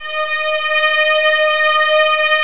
VIOLINS .3-L.wav